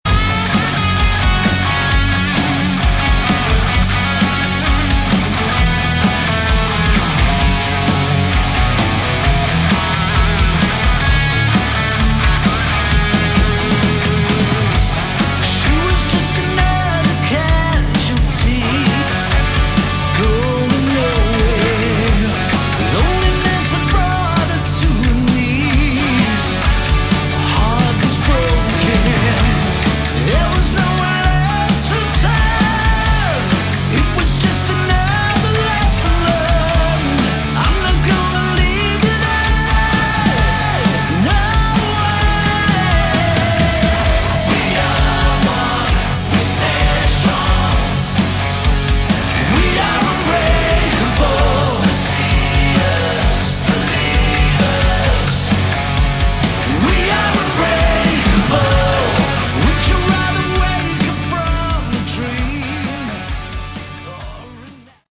Category: Melodic Rock
vocals
guitar
keyboards
bass
drums